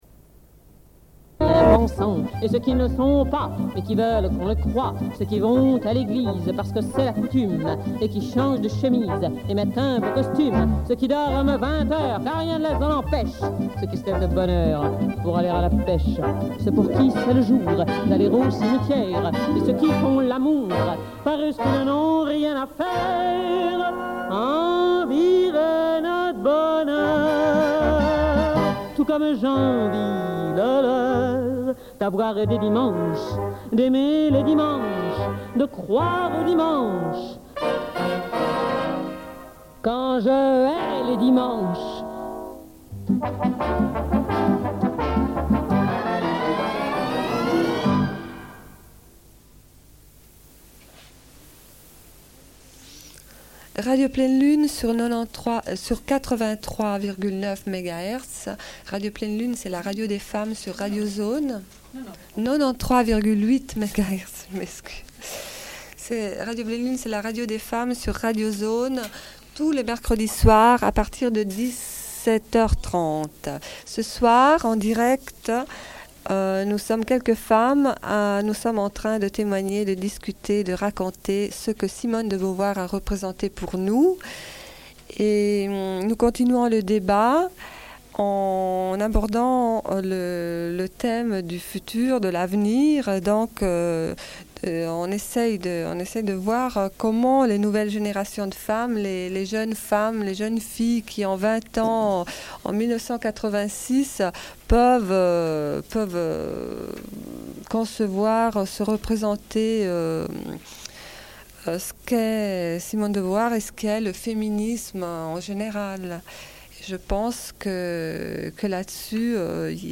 Une cassette audio, face B31:27